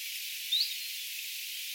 tuollainen ilmeisesti tiltalttilinnun ääni